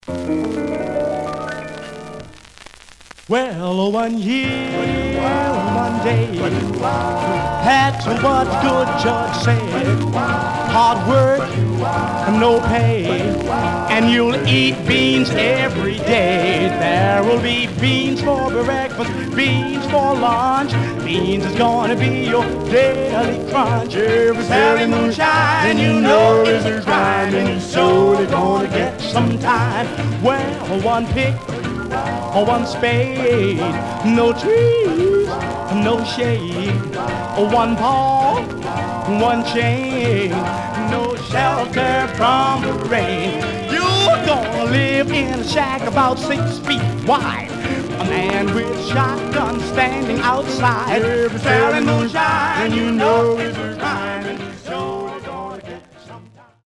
Much surface noise/wear
Mono
Male Black Groups